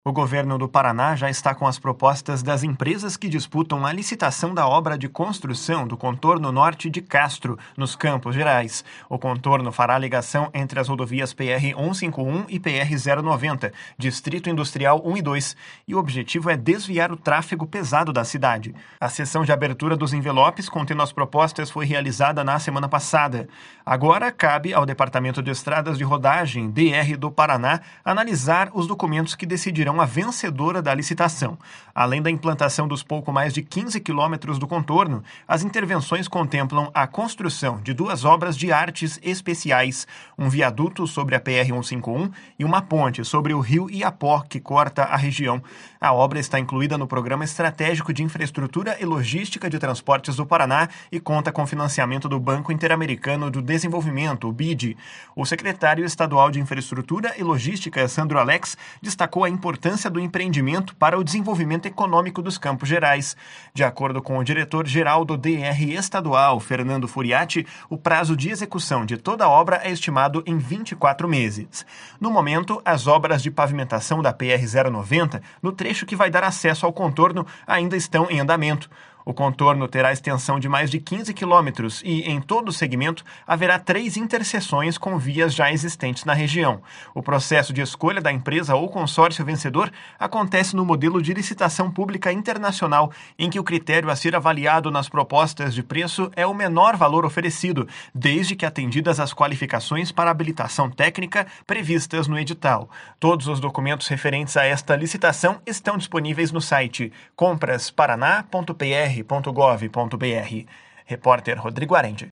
O secretário estadual de Infraestrutura e Logística, Sandro Alex, destacou a importância do empreendimento para o desenvolvimento econômico dos Campos Gerais. De acordo com o diretor-geral do DER Estadual, Fernando Furiatti, o prazo de execução de toda a obra é estimado em 24 meses.